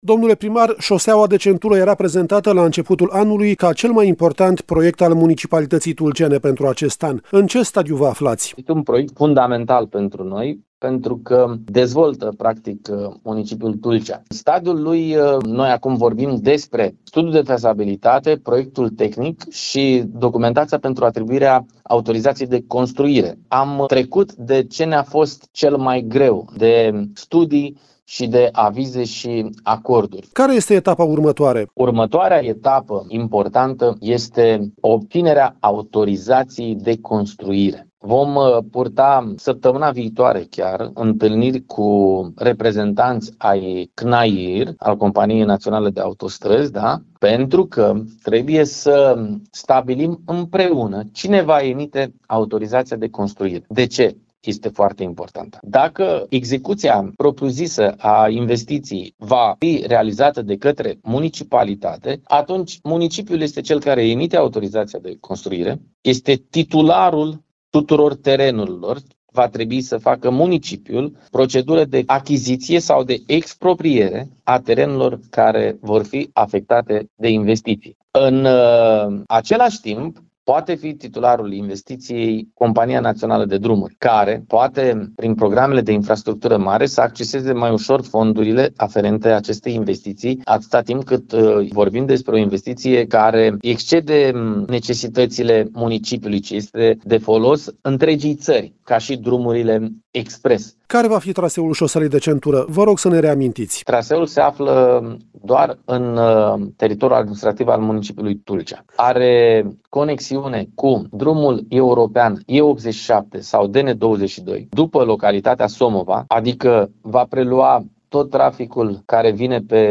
Autoritățile tulcene cred că cei 9 kilometri ai șoselei de centură vor fi gata peste 3 ani. Amănunte, în interviul